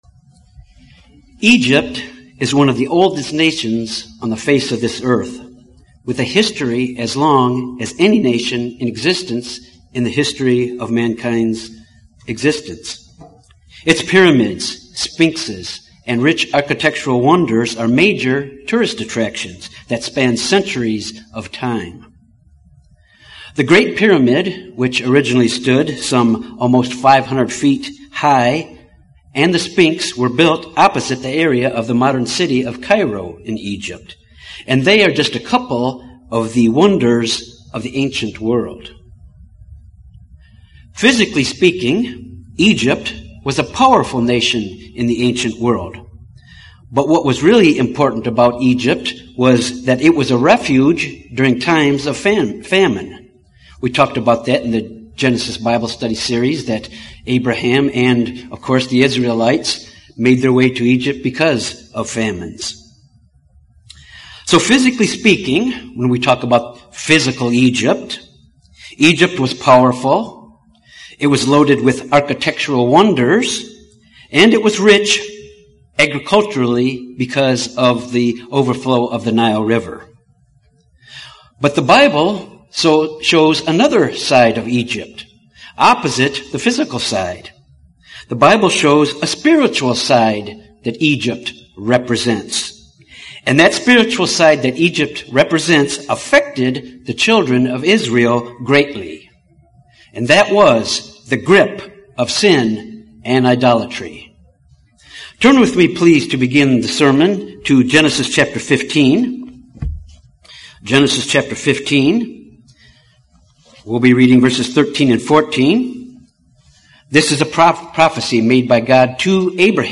Israel had to come out of the slavery of Egypt and the grip that Egypt held on them. This sermon examines how we can come out of the grip of Egypt, symbolic of our coming out of the grip of sin.